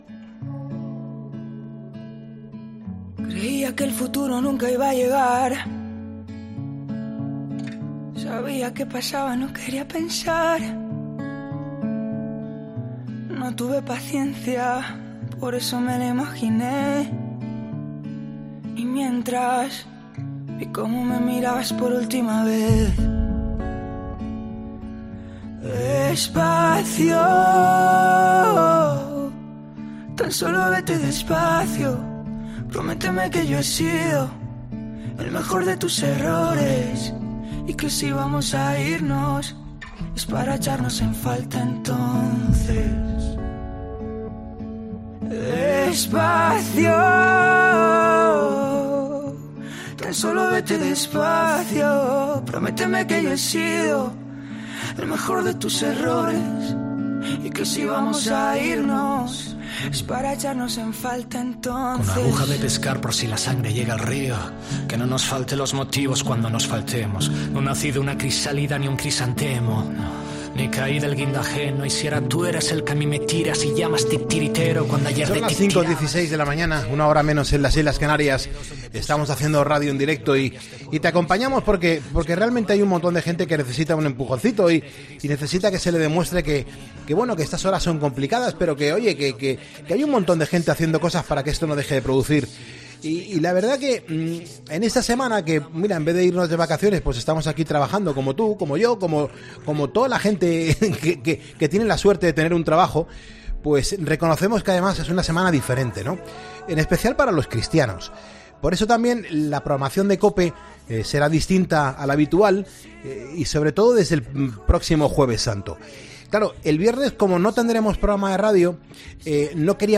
charla con Rayden, un músico, cantante y compositor que ha sabido marcar su propio estilo